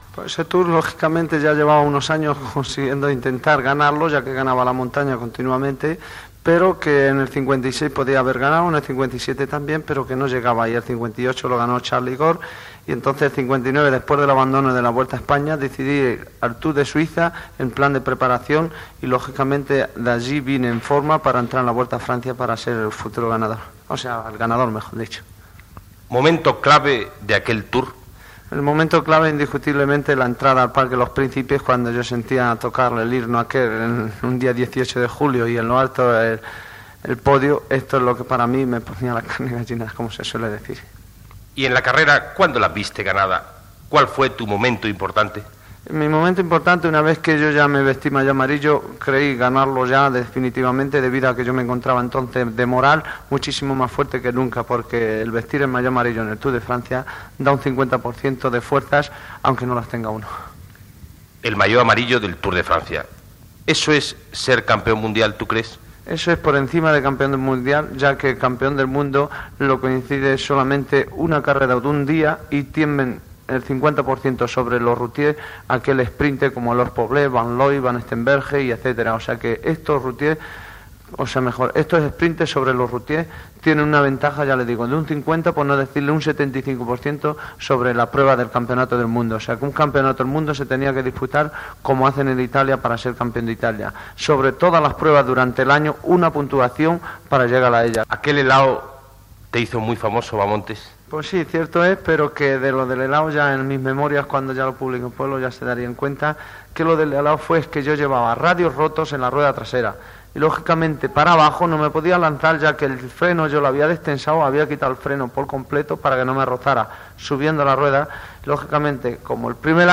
Entrevista al ciclista Federico Martín Bahamontes guanyador del Tour de França
Esportiu